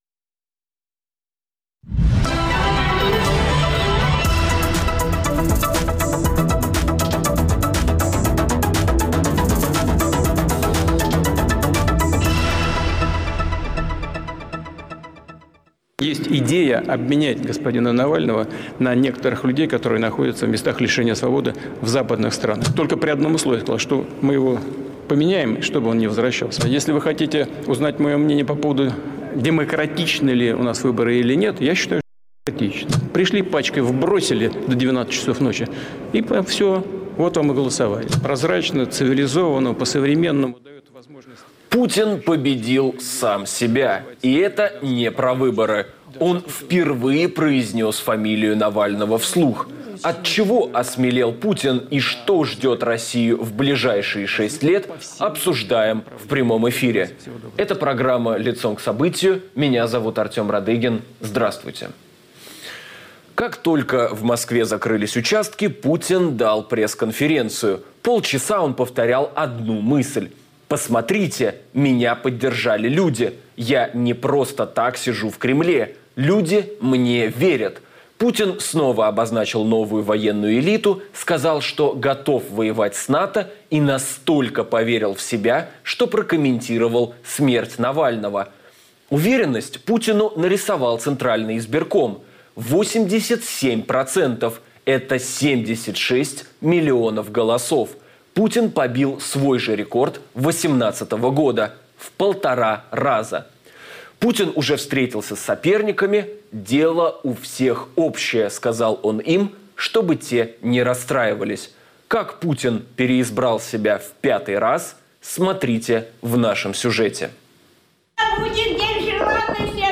Что ждет Россию в ближайшие шесть лет после новых выборов? Обсуждаем в прямом эфире с политиком Дмитрием Гудковым